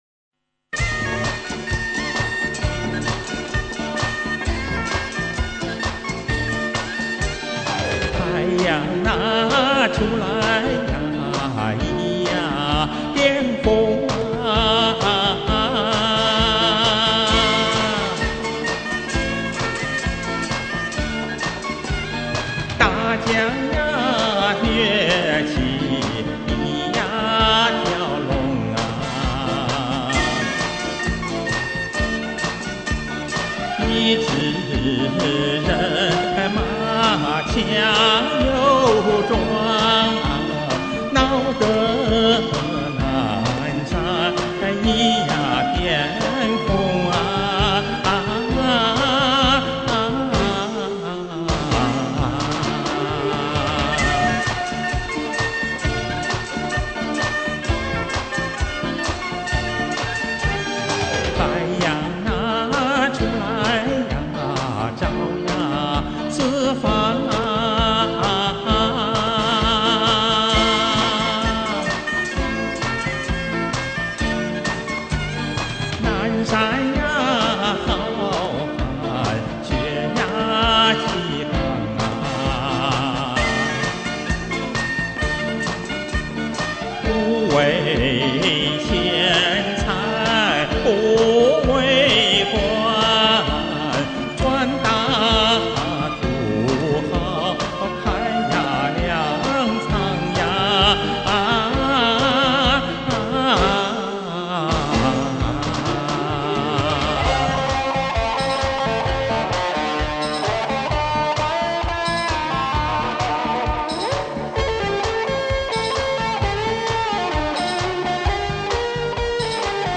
演唱语气亲切豪爽，声音圆润通畅，音色热情优美，感情细腻而又真切。